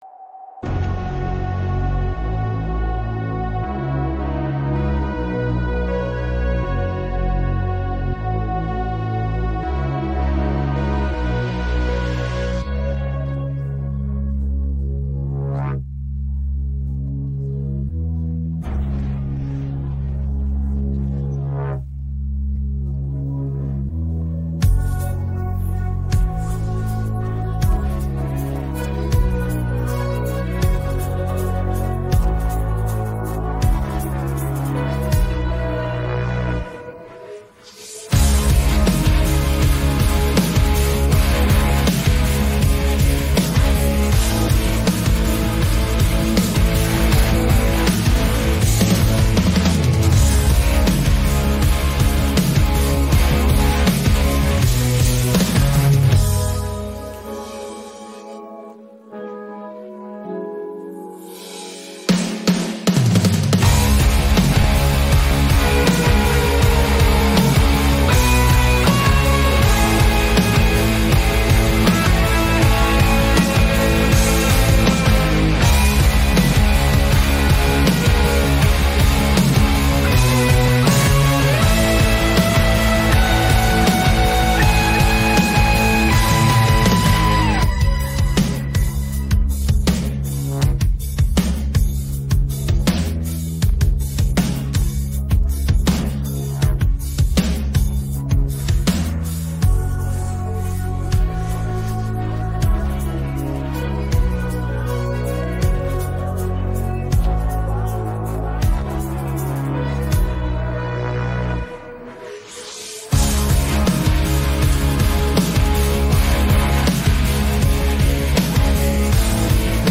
Співайте разом та насолоджуйтесь енергійним звучанням!
Українські хіти караоке